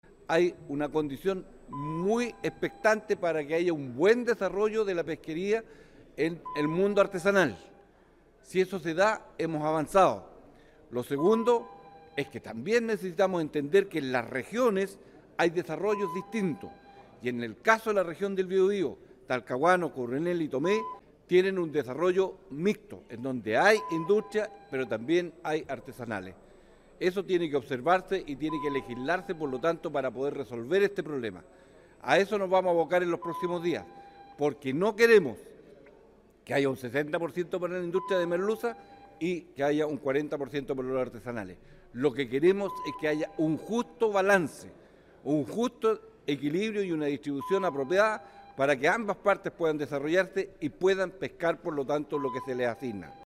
Tras la sesión, el senador Gastón Saavedra (PS) criticó a parlamentarios de su propio partido por “el desapego que tienen con las y los trabajadores de la industria”, en referencia al anuncio de cierre realizado por Pacific Blu, y, en relación con el trabajo que sostendrán en los próximos días, sostuvo que “lo que queremos es que haya un justo equilibrio y una distribución apropiada para que ambos sectores, artesanal e industrial, puedan desarrollarse”.